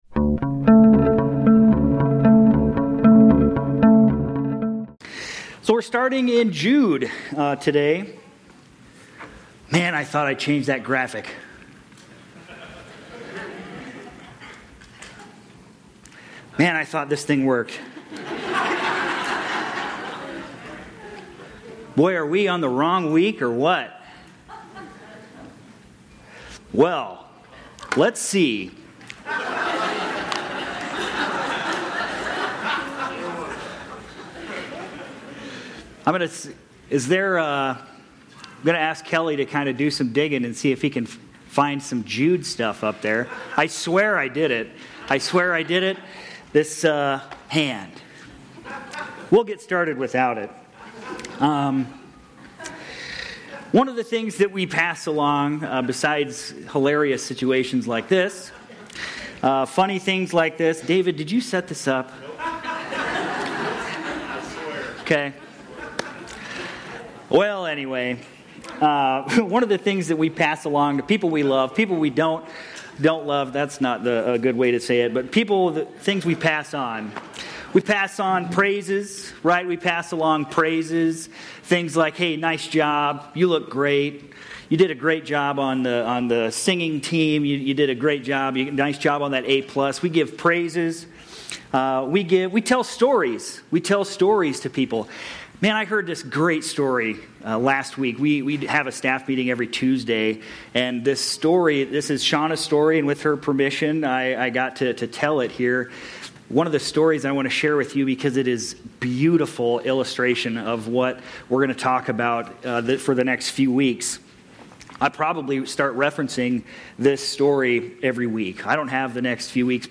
Aug-10-25-Sermon-Audio.mp3